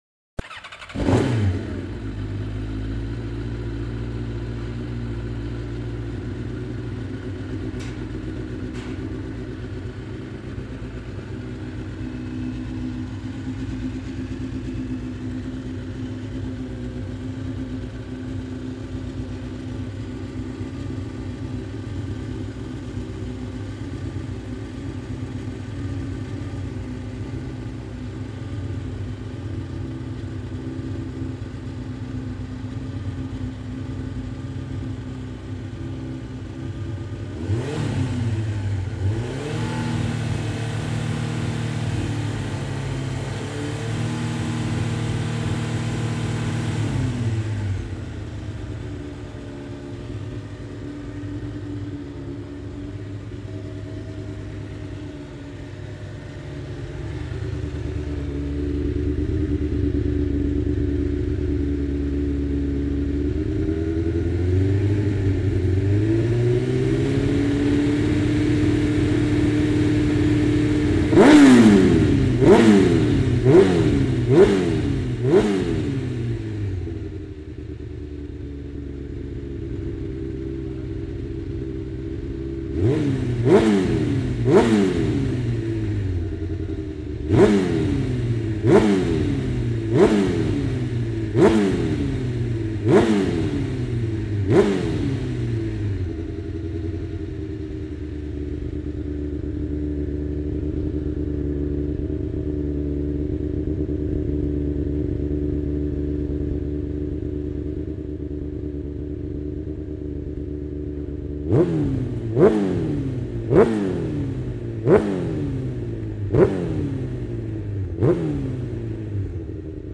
oreilles avec de délicieux chants de moteurs et d'échappements qui nous font si souvent
fond d'un box.mp3